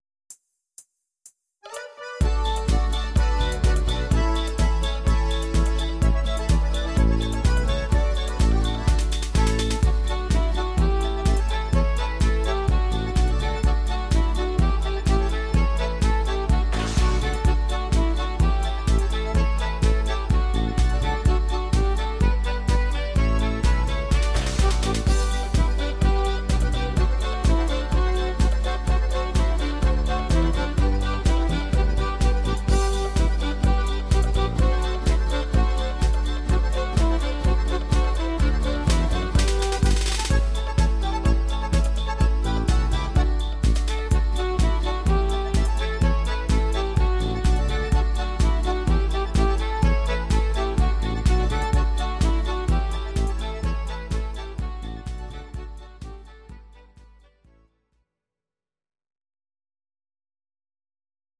Audio Recordings based on Midi-files
German, 2010s, Volkstï¿½mlich